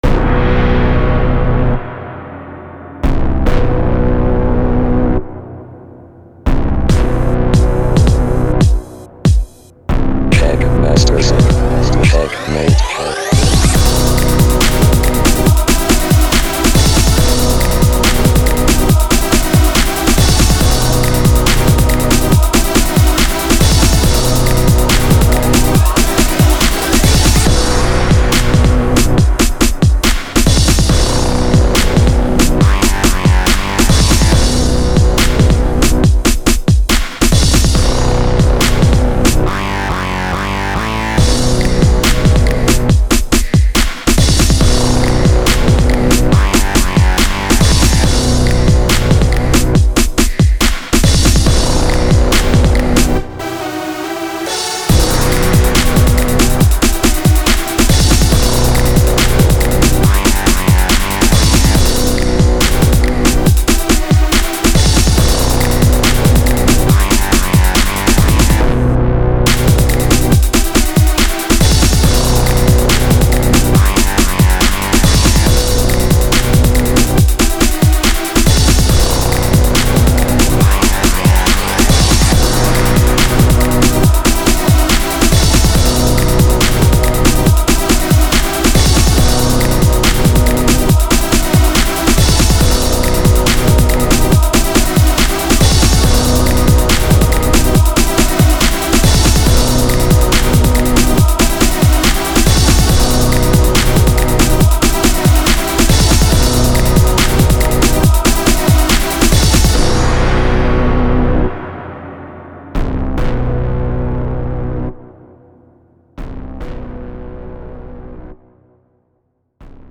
어둡고... 웅장하게...
동시에 질리도록 반복적으로...
루프는 두마디 이하로...
드럼은 이상할 정도로 화려하게...